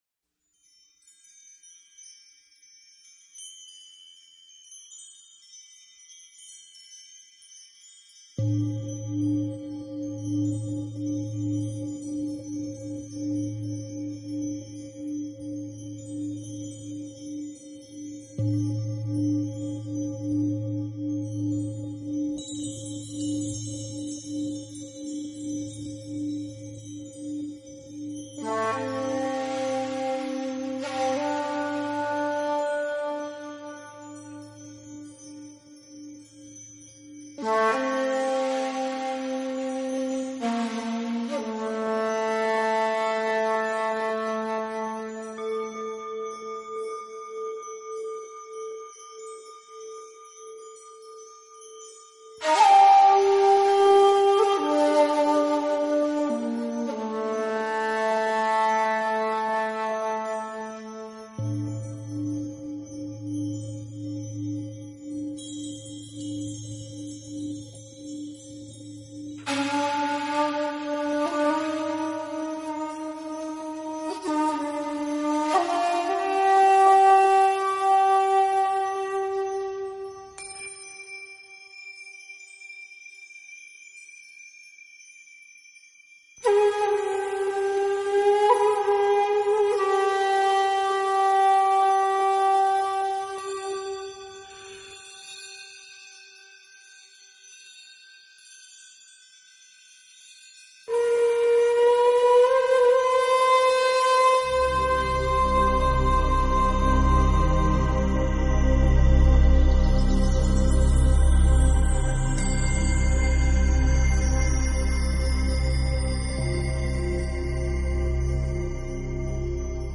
【新世纪纯音乐】
音乐流派：New Age